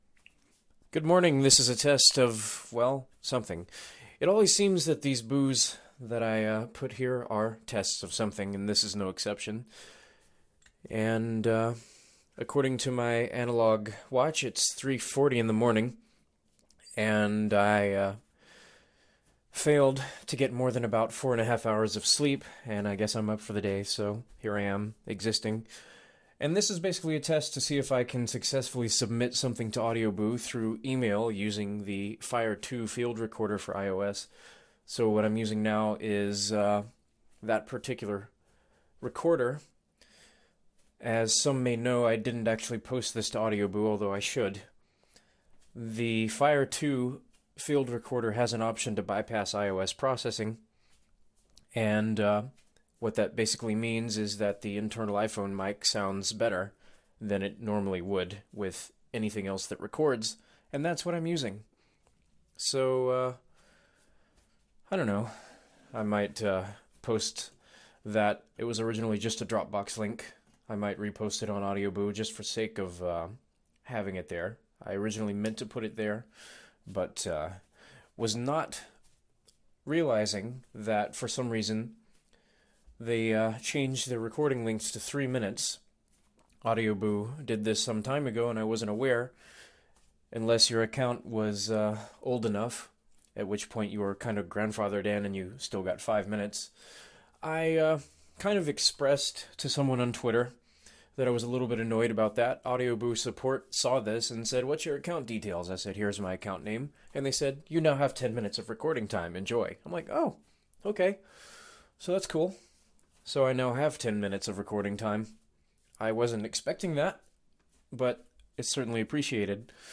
A quick, boring test of email submission from FiRe field recorder